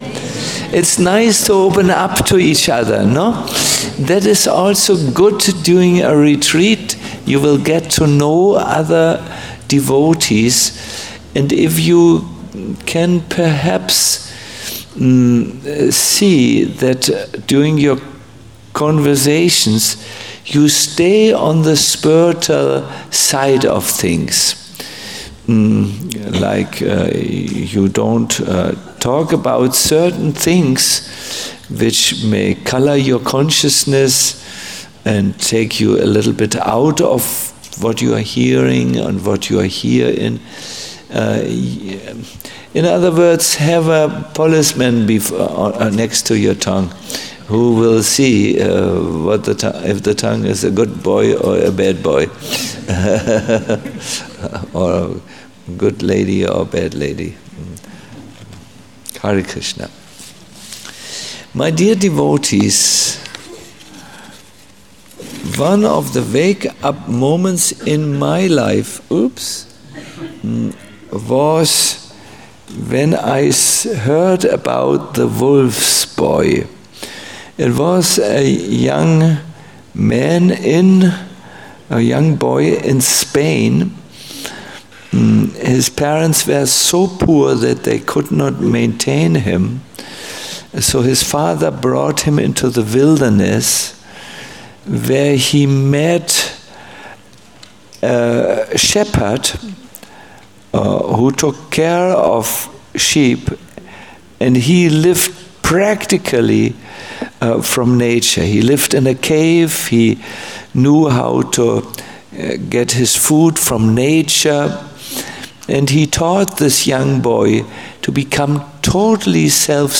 a lecture
Govardhana Retreat Center